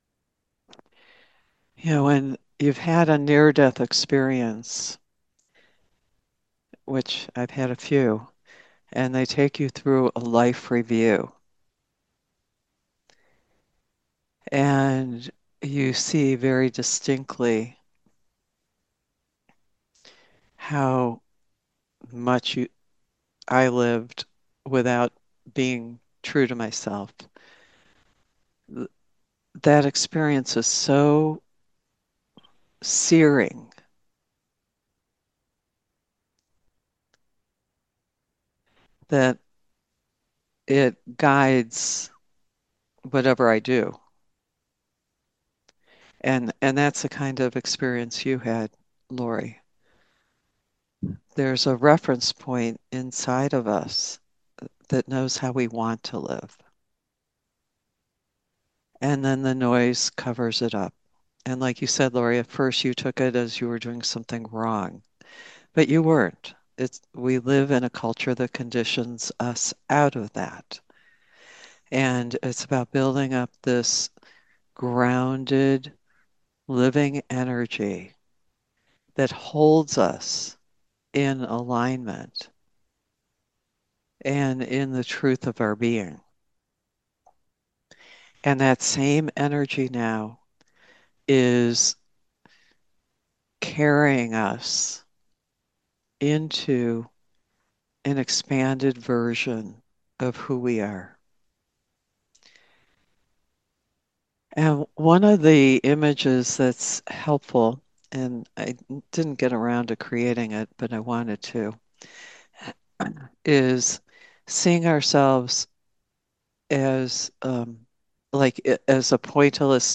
Genre: Guided Meditation.